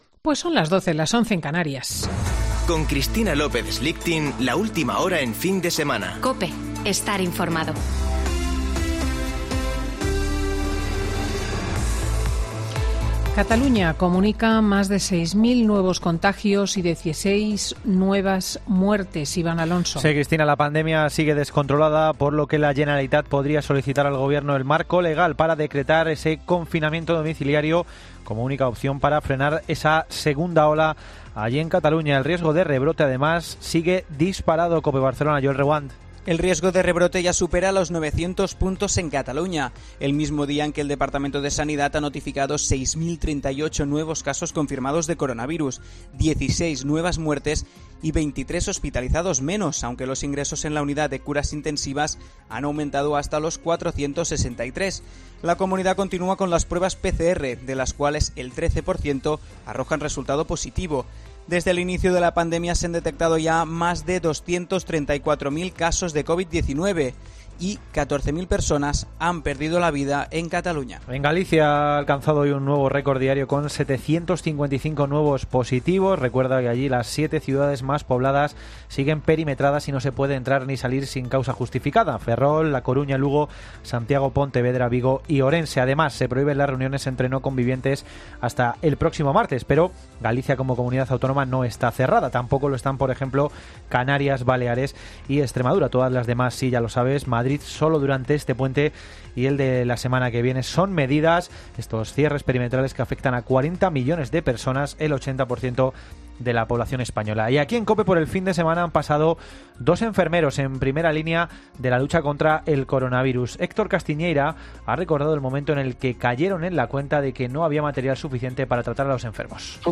Boletín de noticias de COPE del 31 de Octubre de 2020 a las 12.00 horas